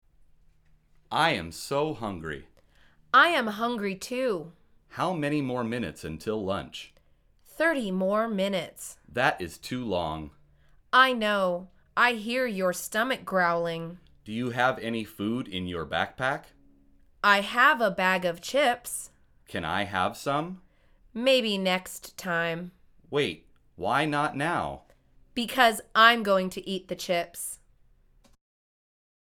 مجموعه مکالمات ساده و آسان انگلیسی – درس شماره دوازدهم از فصل زندگی محصلی: غذا خوردن تو کلاس